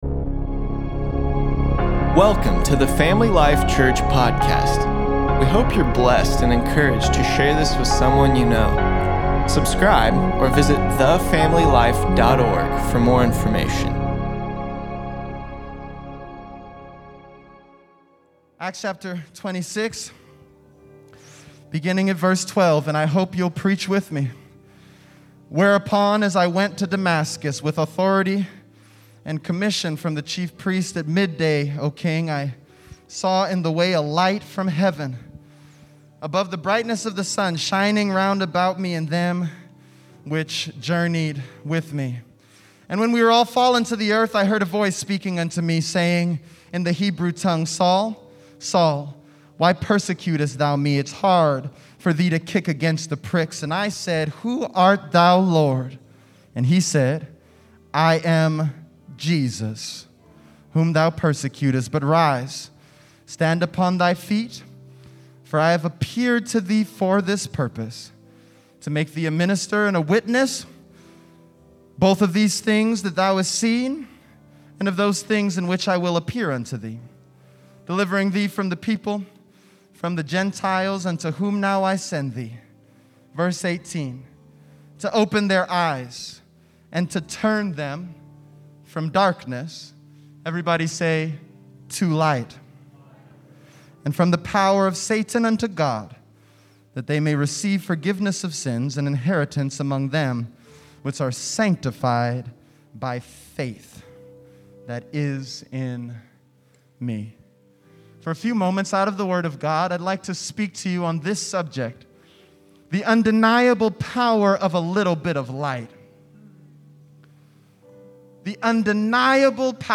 9-20-20_sermon_p.mp3